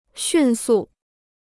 迅速 (xùn sù) Dicionário de Chinês gratuito